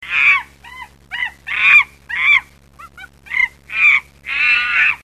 monkey.mp3